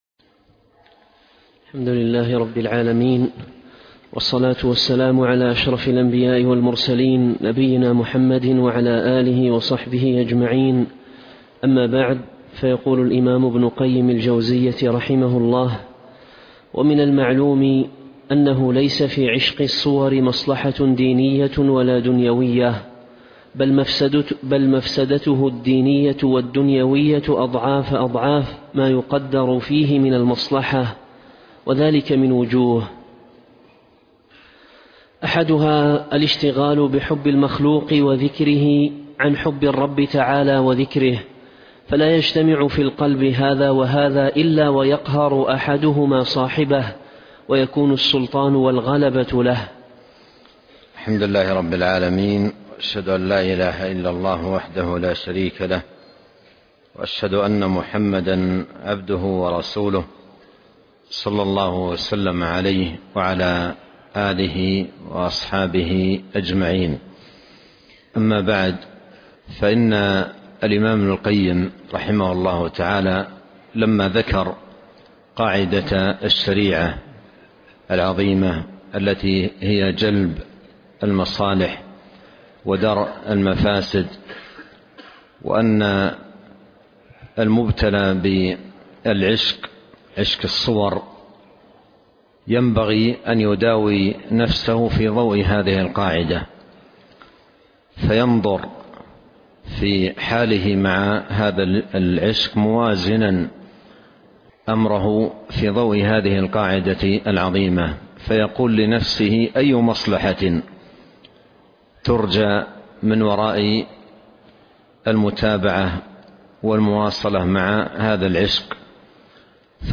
كتاب الداء والدواء - عشق الصور - الدرس 72